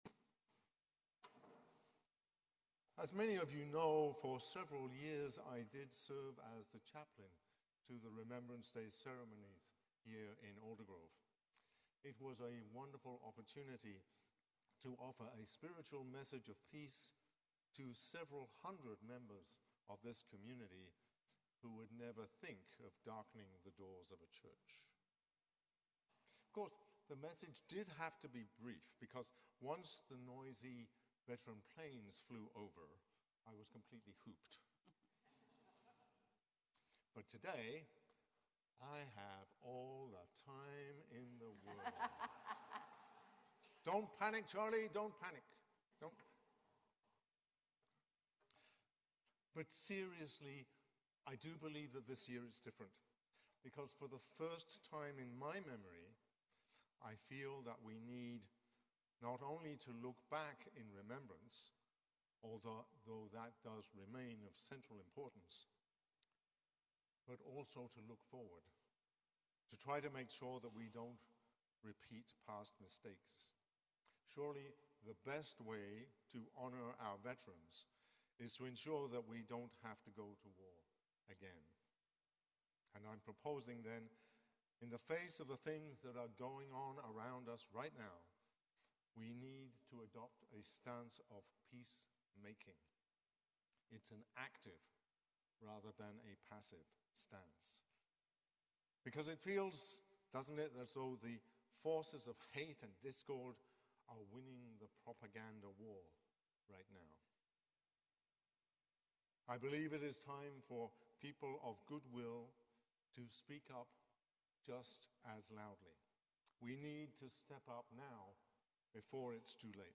Sermon on the Twenty-second Sunday after Pentecost